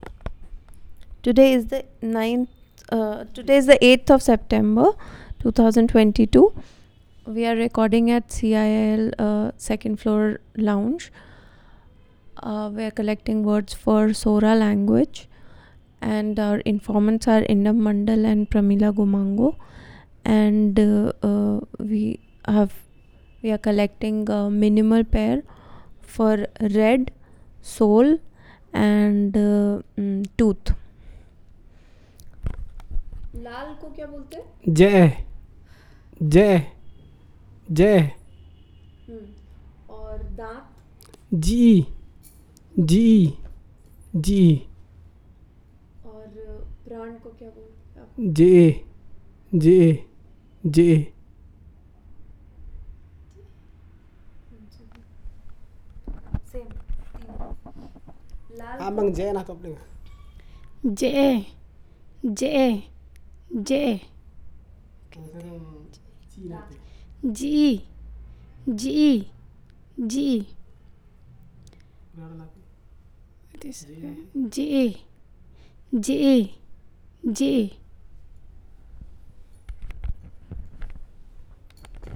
Elicitation of minimal pairs